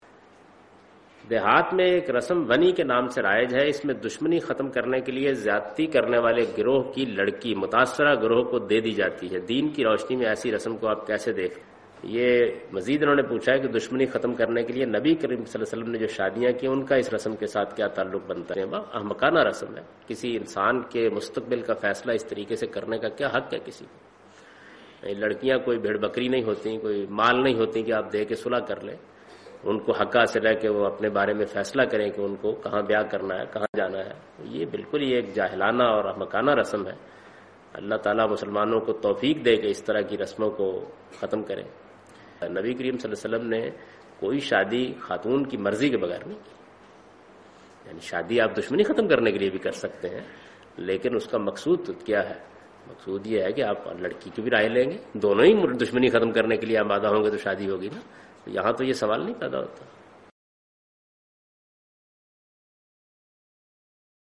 Javed Ahmad Ghamidi responds to the question ' Is the cult of "WANI" allowed in Islam' ?